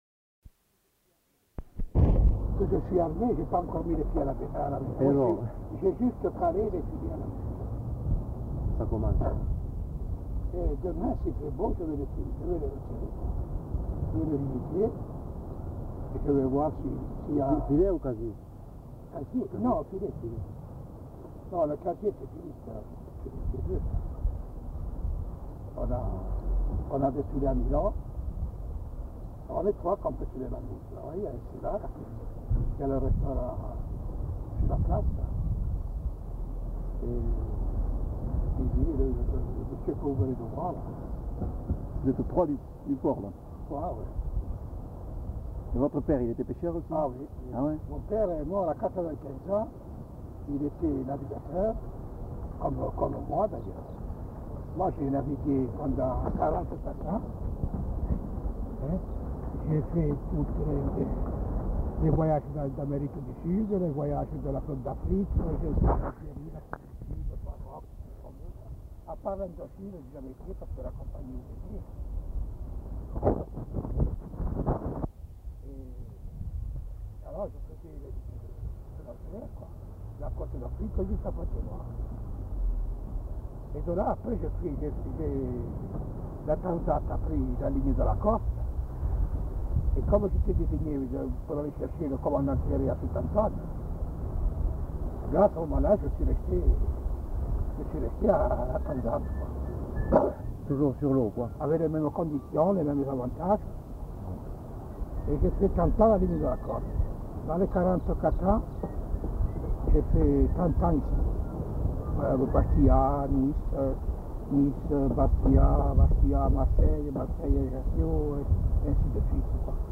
Discussion sur la pêche professionnelle
Lieu : Bastia (région)
Genre : témoignage thématique